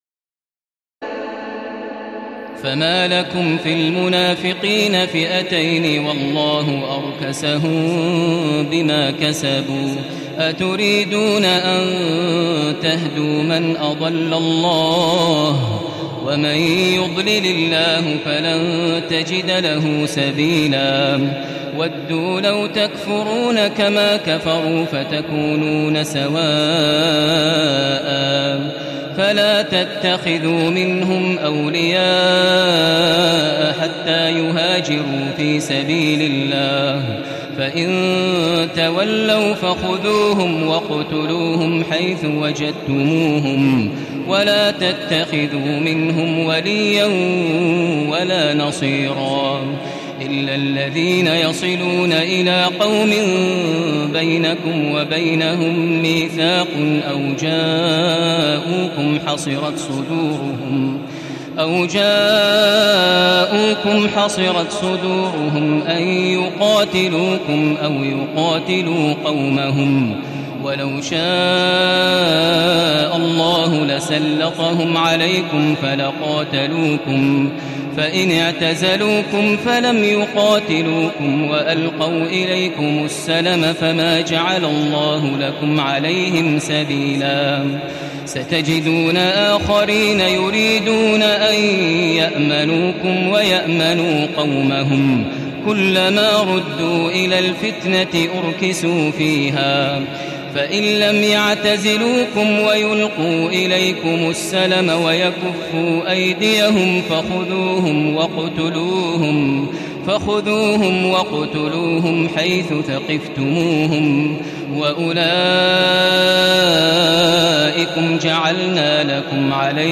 تراويح الليلة الخامسة رمضان 1435هـ من سورة النساء (88-147) Taraweeh 5 st night Ramadan 1435H from Surah An-Nisaa > تراويح الحرم المكي عام 1435 🕋 > التراويح - تلاوات الحرمين